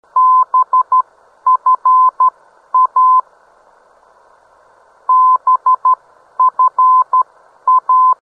BFA - Boyne Falls heard on 263 kHz: (57kb)